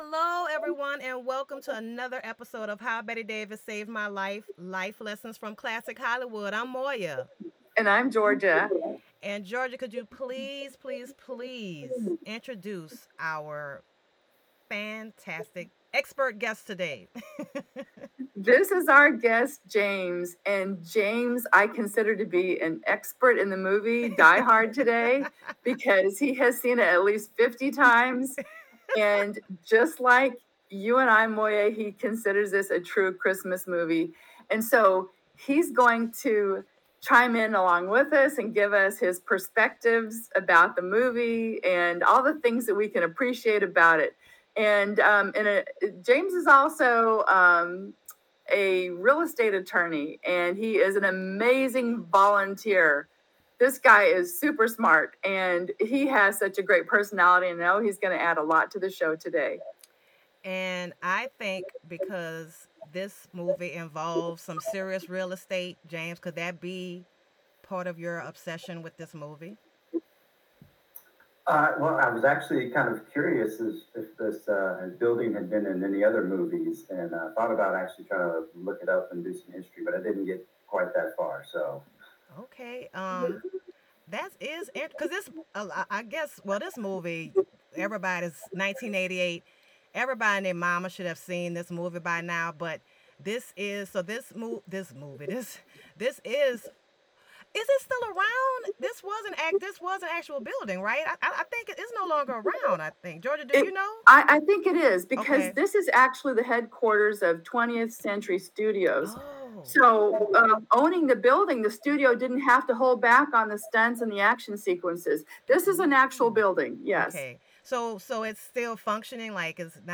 Some German robbers say, "Nein"! w/ special guest co-host!